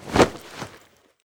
ak74_bayonet.ogg